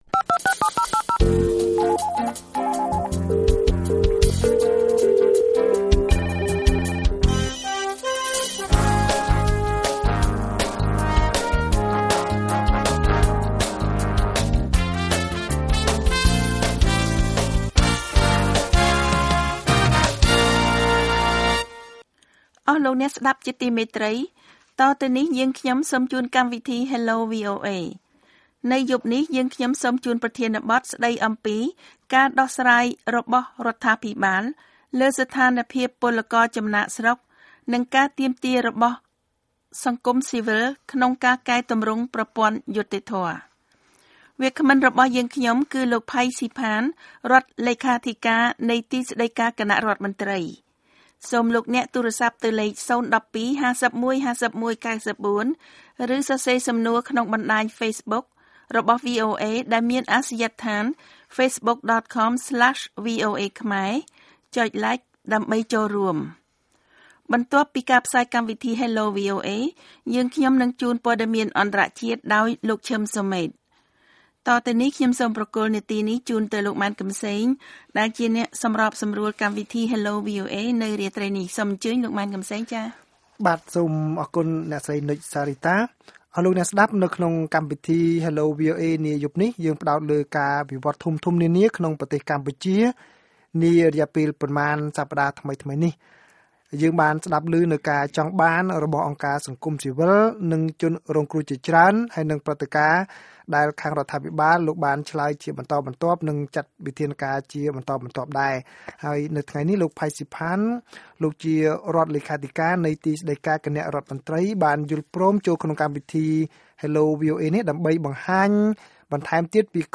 លោក ផៃ ស៊ីផាន រដ្ឋលេខាធិការនៃទីស្តីការគណៈរដ្ឋមន្ត្រី ពន្យល់ពីគោលជំហរចុងក្រោយរបស់រដ្ឋាភិបាលស្ថានភាពពលករចំណាកស្រុកការការទាមទាររបស់សង្គមស៊ីវិលក្នុងការកែទម្រង់ប្រព័ន្ធយុត្តិធម៌។